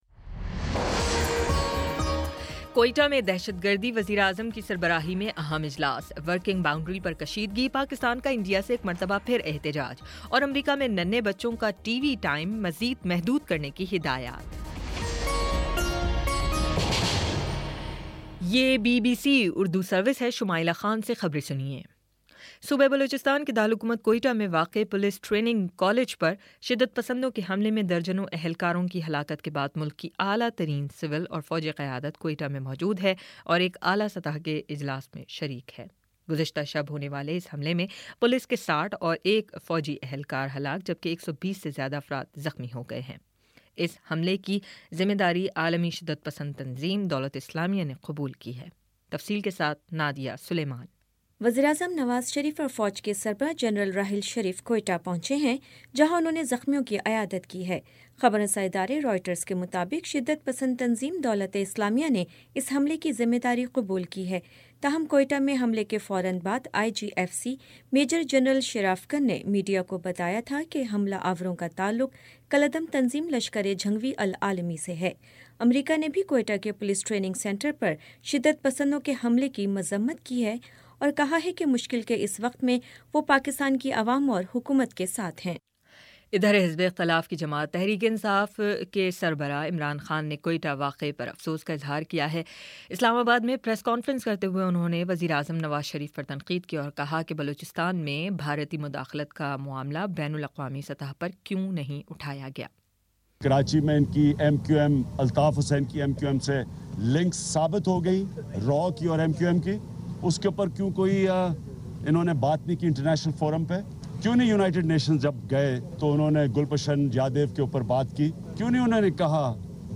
اکتوبر 25 : شام چھ بجے کا نیوز بُلیٹن